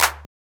CC - Motto Clp.wav